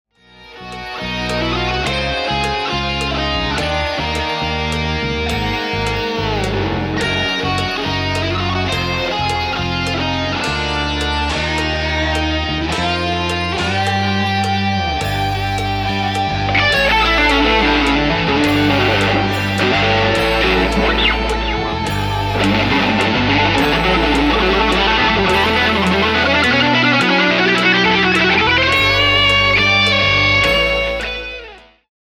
A play-along track in the style of fusion.